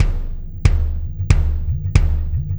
Kick Particle 07.wav